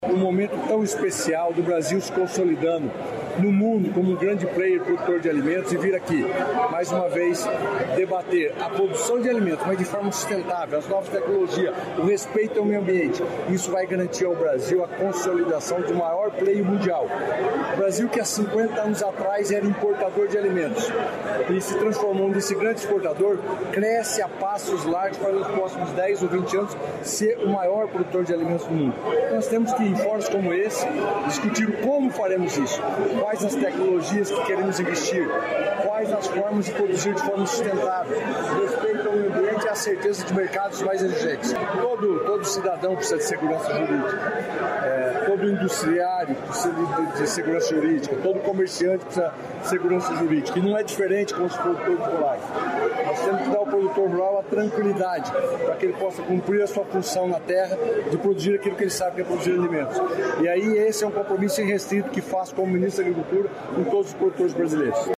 Sonora do ministro da Agricultura, Pecuária e Abastecimento, Carlos Fávaro, sobre exemplo de inovação e sustentabilidade no Paraná
CARLOS FÁVARO - LONDRINA.mp3